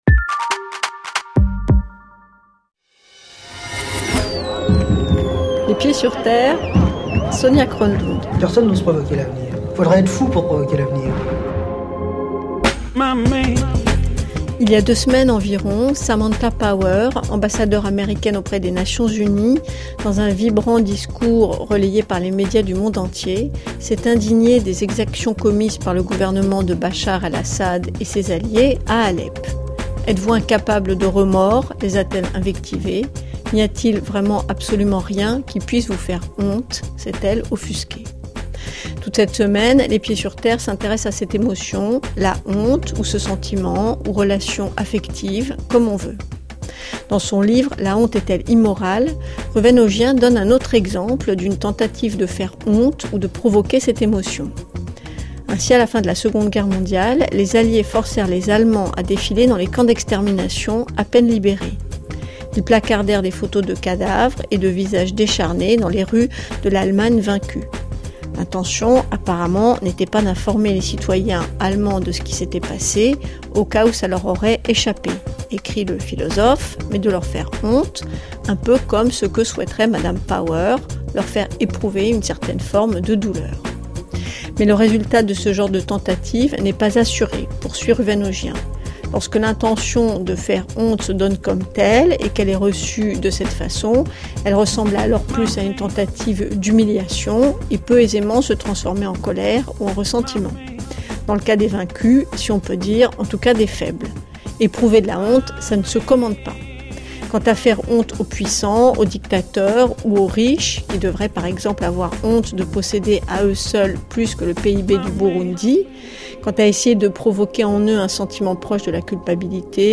Trouver 3 personnes prêtes à raconter une chose si personnelle n’a pas été chose aisée m’a dit cette journaliste et vous entendrez dans ce podcast 2 hommes et moi qui racontent leur expérience sur la honte, 3 expériences totalement différentes mais avec un résultat identique la honte a empoisonné l’existence de chacun d’entre nous.